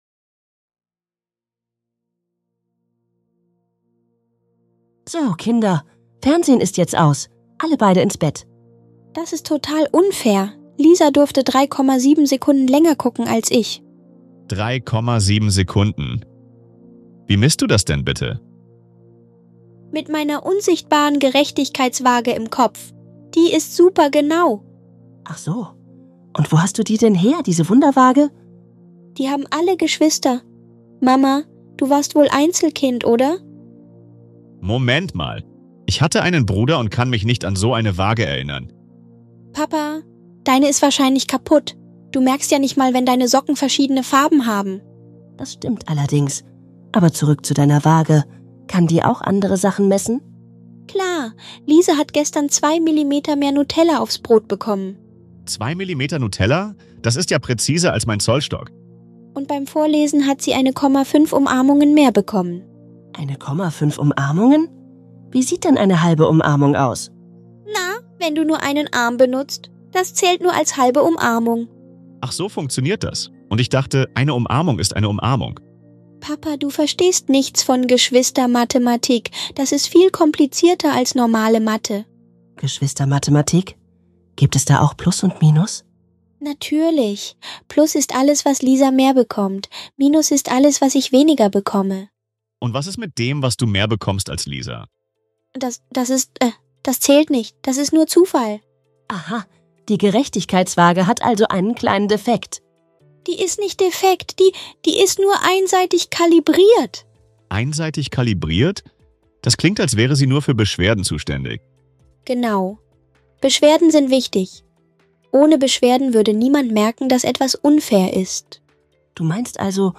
In diesem super lustigen Dialog